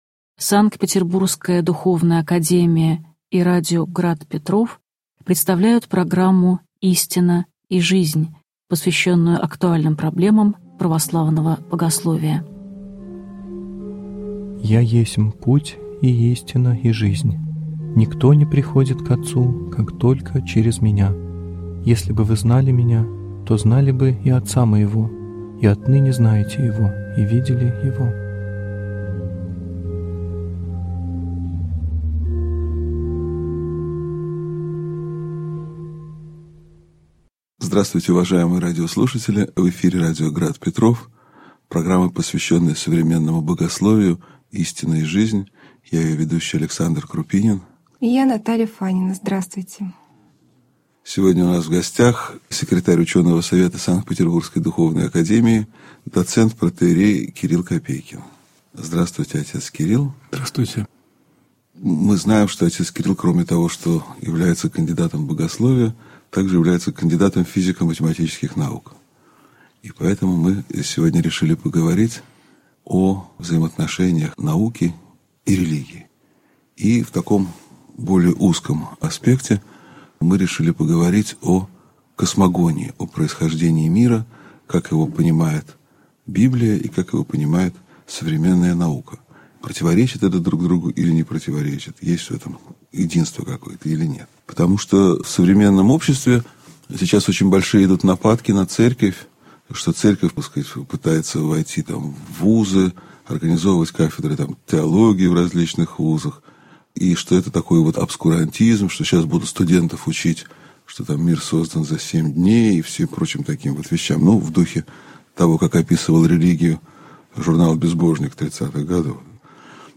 Аудиокнига Космогония (часть 1) | Библиотека аудиокниг